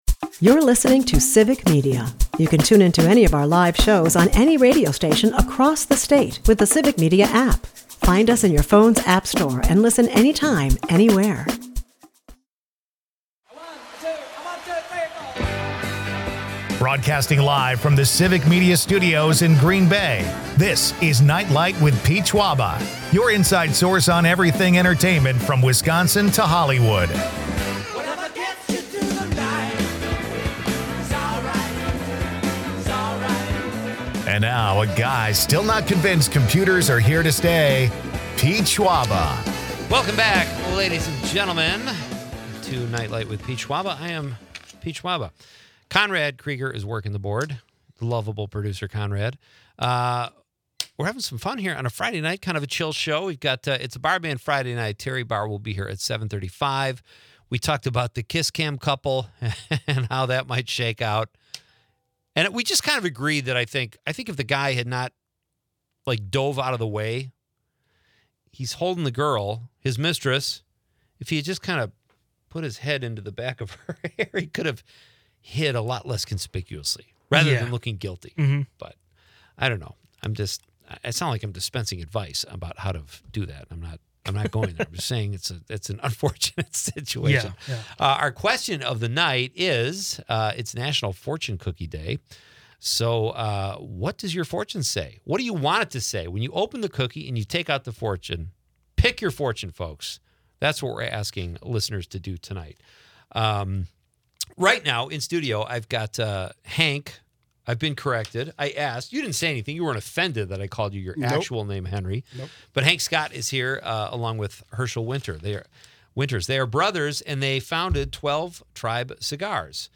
They delve into cigar-making intricacies, including how to revive a half-smoked cigar. Listeners chime in with fortune cookie wishes, celebrating National Fortune Cookie Day.